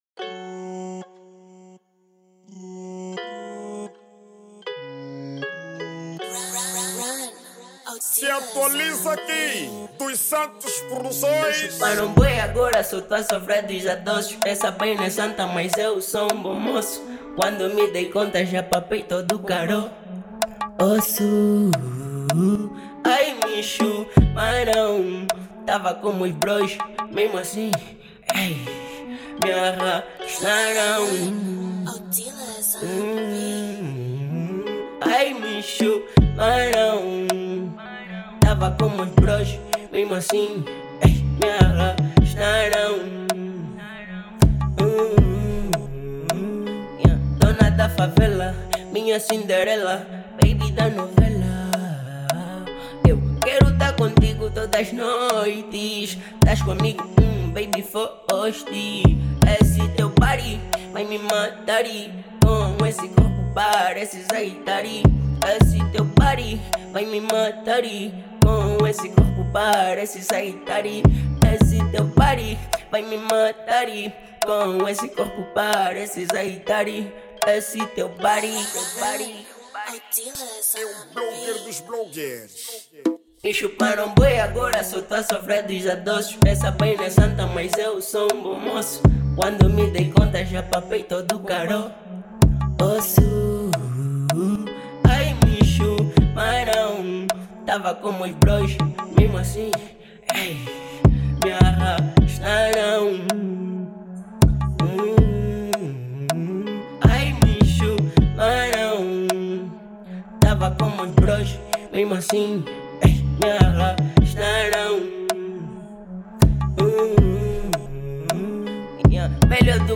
ESTILO DA MÚSICA:  Trap Funk
MUSIC STYLE: Trap Funk